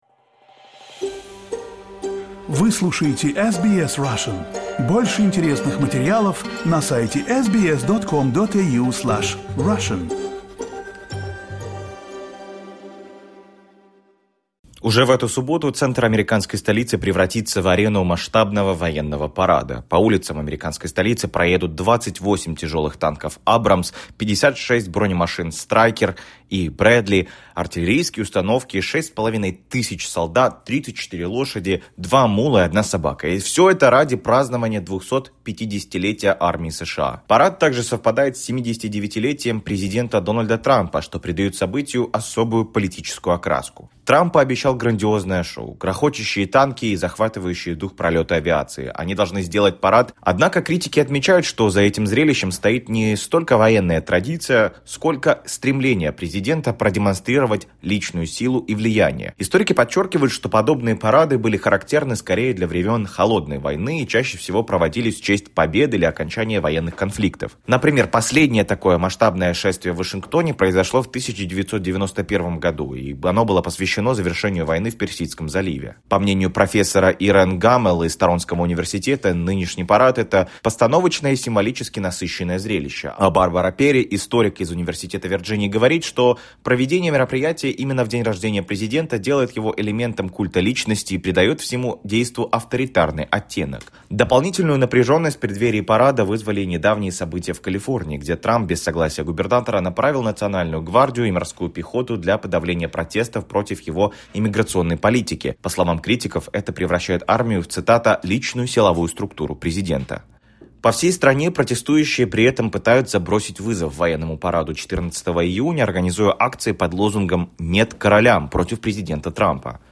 Что говорят о предстоящей демонстрации военной техники в американской столице, слушайте в нашем репортаже.